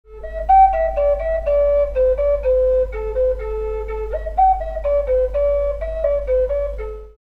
Пимак A Тональность: A
Проста в исполнении, но имеет вполне достойное звучание.
Традиционно представляет из себя трубку с шестью игровыми отверстиями и свистка, выполненного при помощи накладки, обычно в виде тотемного животного.